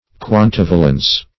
Search Result for " quantivalence" : The Collaborative International Dictionary of English v.0.48: Quantivalence \Quan*tiv"a*lence\, n. [L. quantus how much + E. valence.]